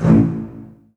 strTTE65005string-A.wav